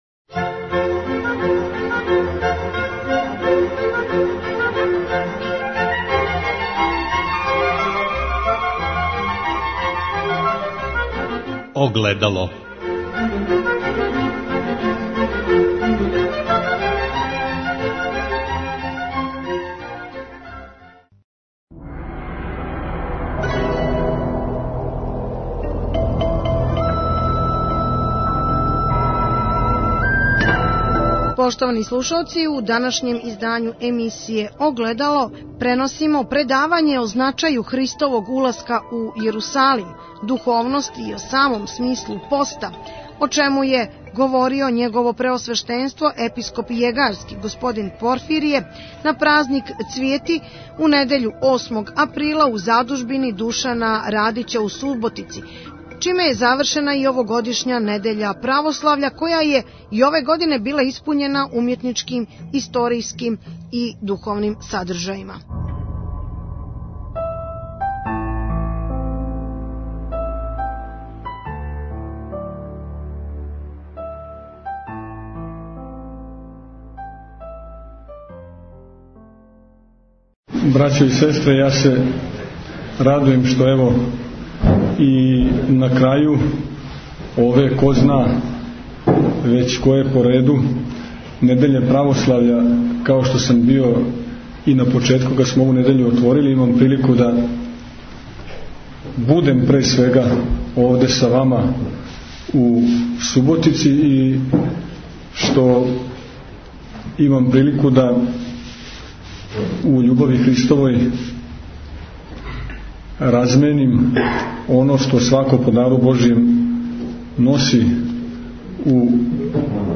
На празник Цвијети, у недјељу 8. априла 2012. године, у Задужбини Душана Радића у Суботици, духовном трибином завршена је овогодишња Недеља Православља, која је и ове године била испуњена умјетничким, историјским и духовним садржајима. На велику духовну радост и корист свих Суботичана, о значају Христовог уласка у Јерусалим, духовности и о самом смислу поста, бесједио је Његово Преосвештенство Епископ јегарски Г. Порфирије.